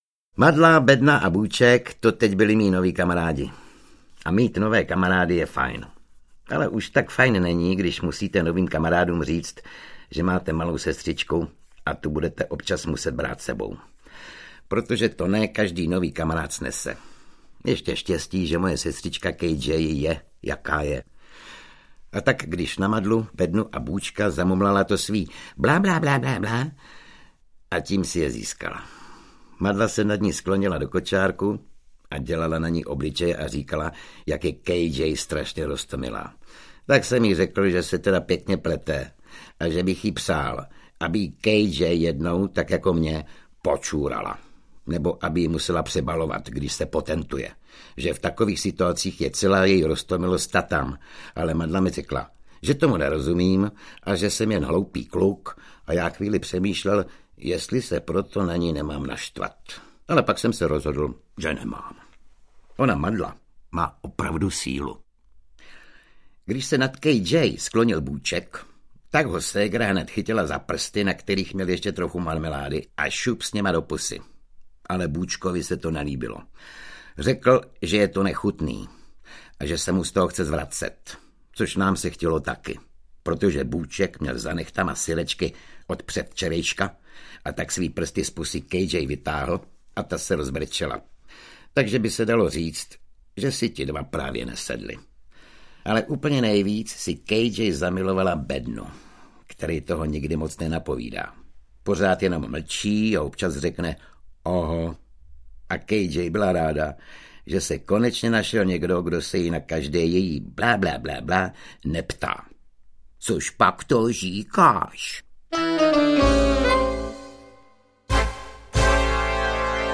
Dobrodružství Billa Madlafouska audiokniha
Audiokniha Dobrodružství Billa Madlafouska, kterou napsal David Laňka - obsahuje deset příběhů z úspěšné knížky pro děti v neodolatelném podání Oldřicha Kaisera.
Ukázka z knihy